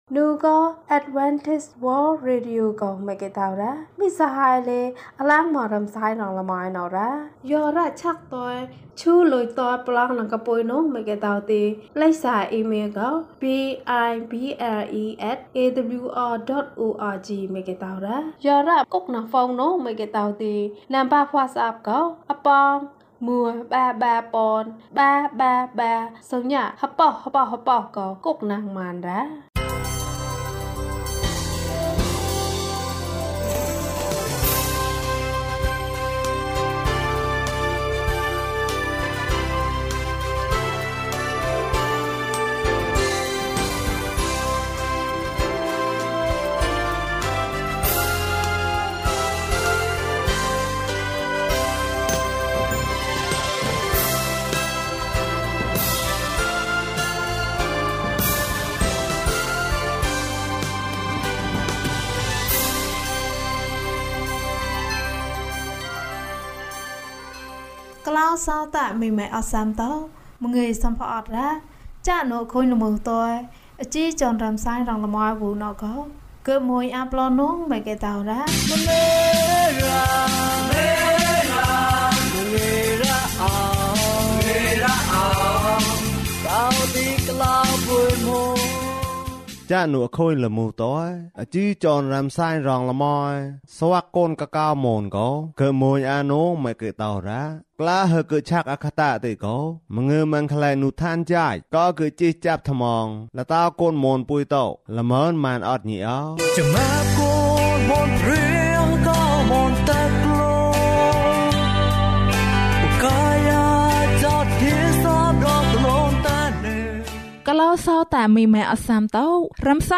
ယေရှုမင်းကိုချစ်တယ် အပိုင်း ၂။ ကျန်းမာခြင်းအကြောင်းအရာ။ ဓမ္မသီချင်း။ တရားဒေသနာ။